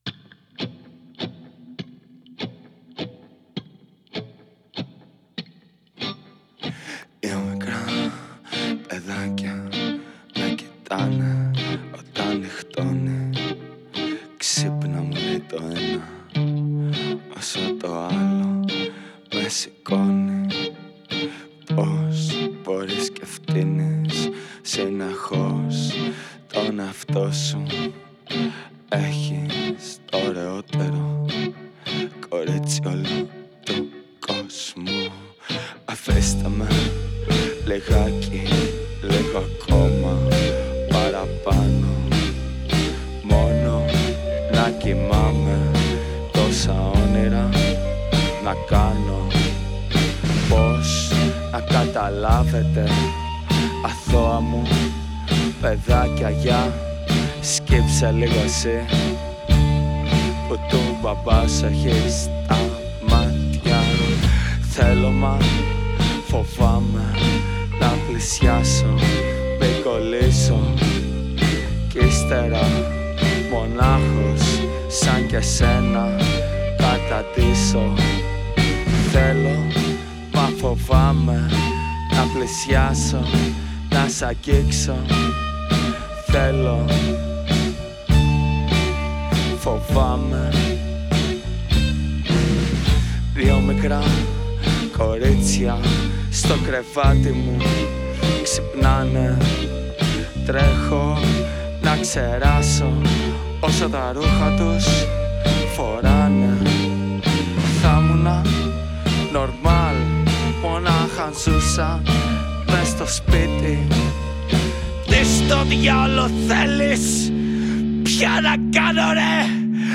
Ζωντανή επανεκτέλεση του τραγουδιού
σόλο κιθάρα
μπάσο
τύμπανα